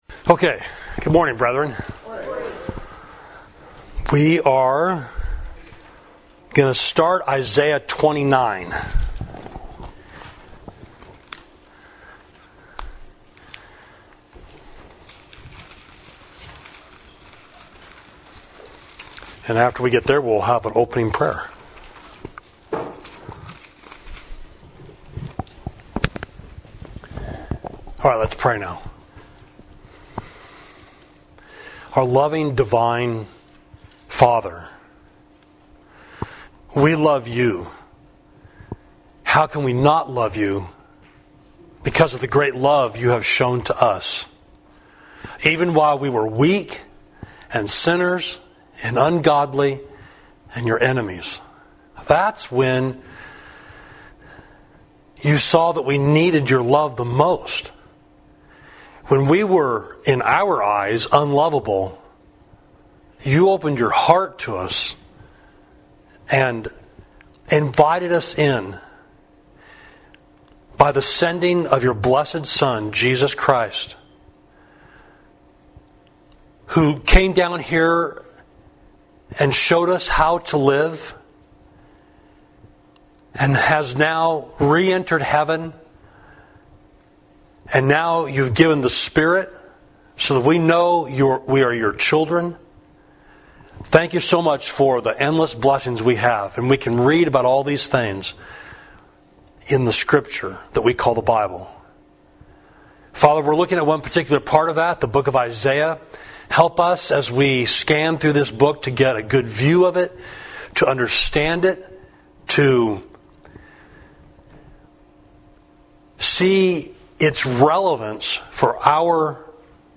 Class: Outline of the Book of Isaiah